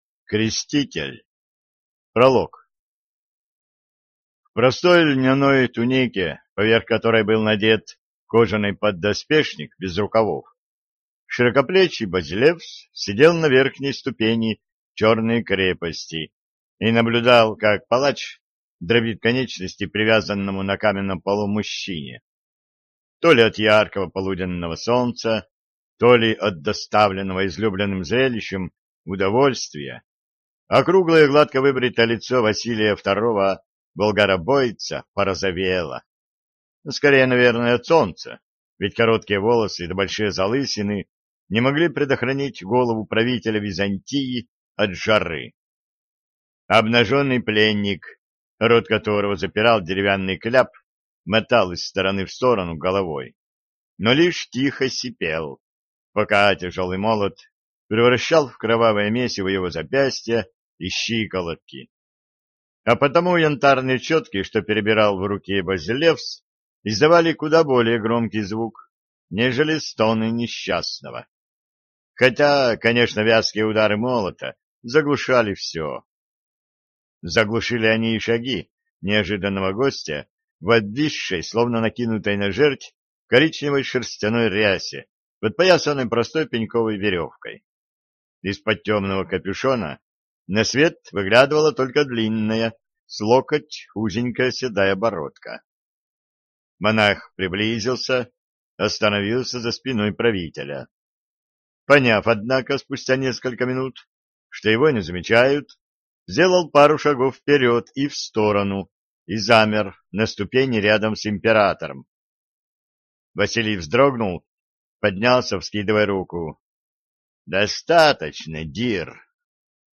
Аудиокнига Креститель | Библиотека аудиокниг
Прослушать и бесплатно скачать фрагмент аудиокниги